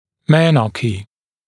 [‘menɑːkiː][‘мэна:ки:]менархе, первая менструация, начало менструаций